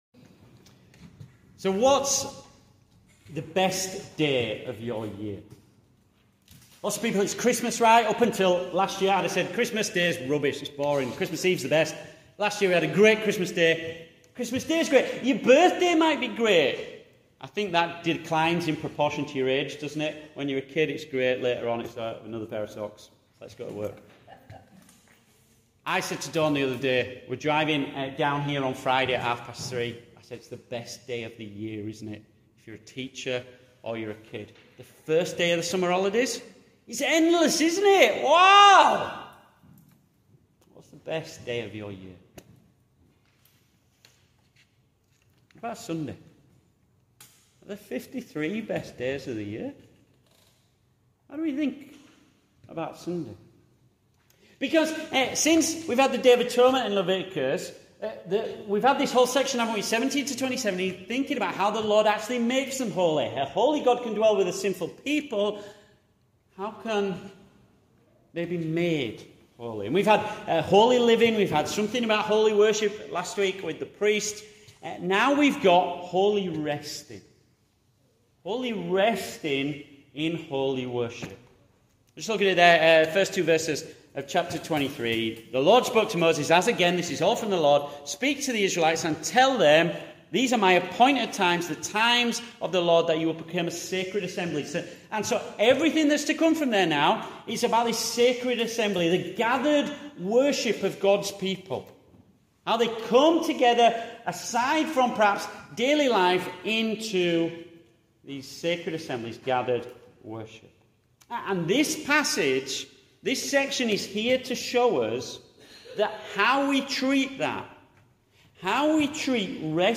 Returning Home Passage: Leviticus 23-25 Service Type: Morning Service « Who may stand in his holy place?